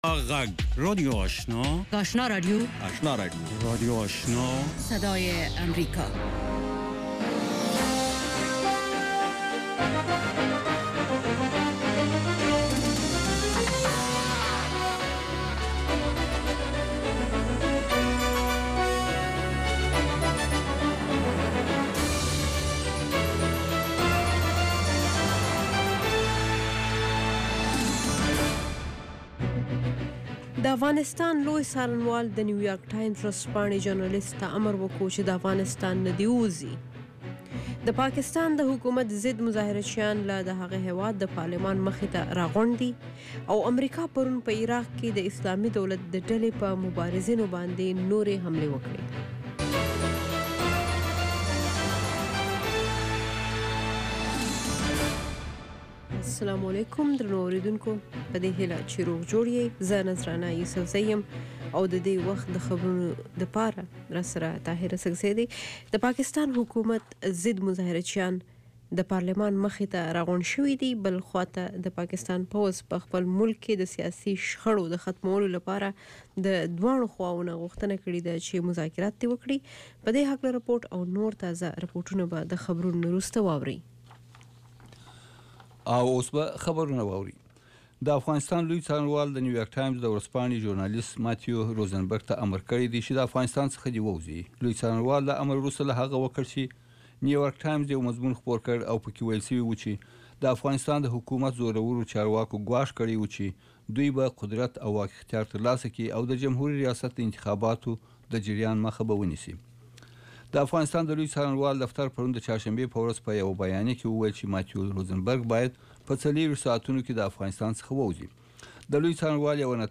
دویمه سهارنۍ خبري خپرونه